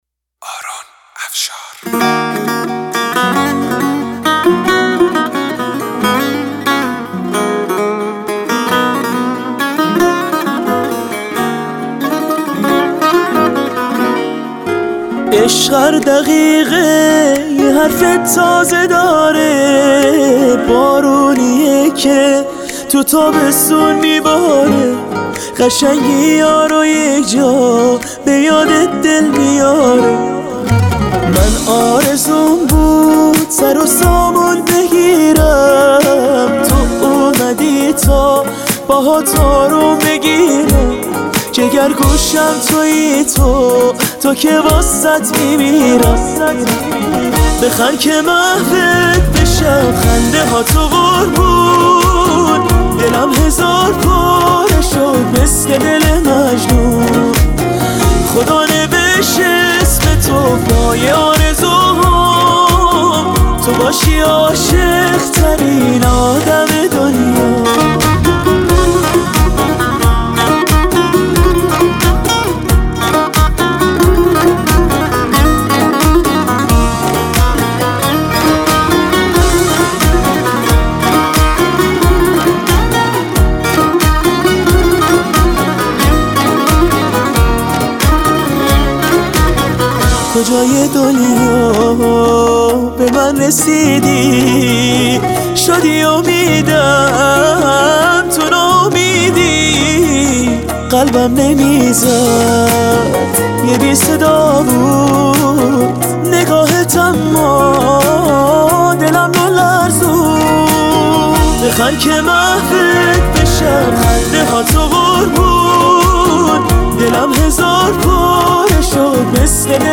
اهنگ ملایم برای سفره عقد